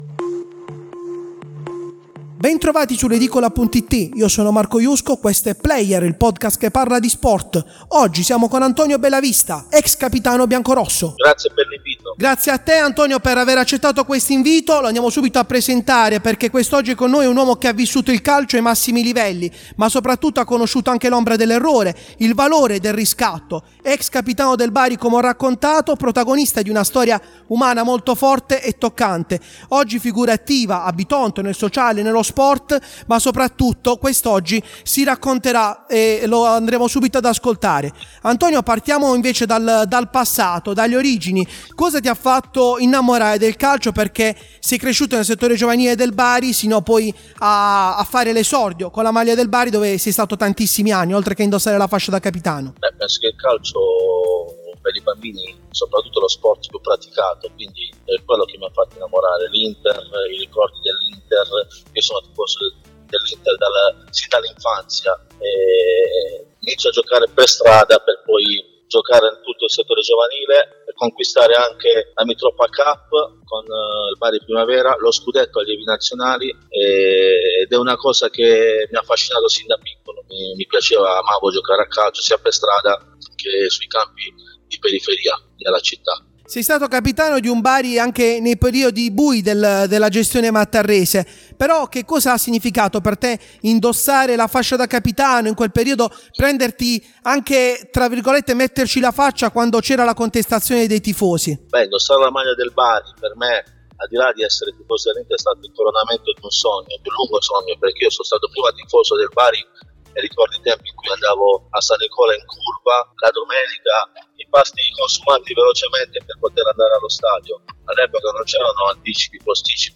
si racconta in un’intervista tra passato, errori e rinascita.